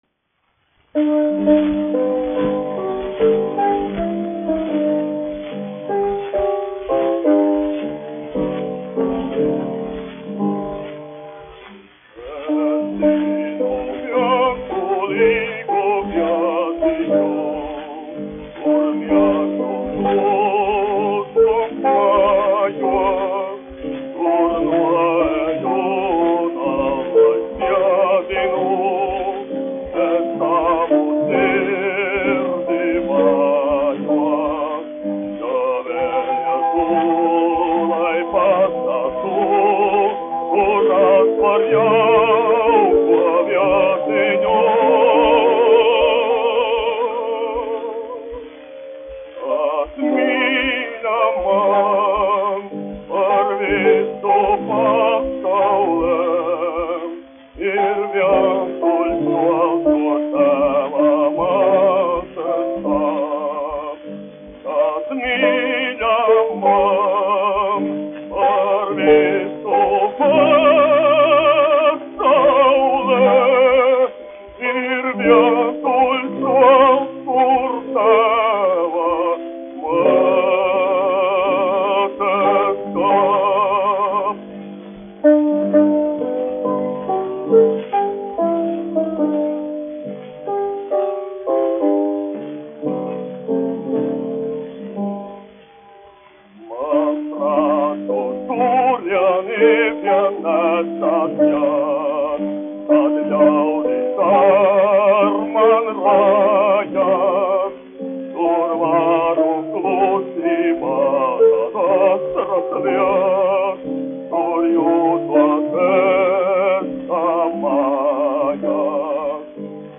1 skpl. : analogs, 78 apgr/min, mono ; 25 cm
Dziesmas (zema balss) ar klavierēm
Latvijas vēsturiskie šellaka skaņuplašu ieraksti (Kolekcija)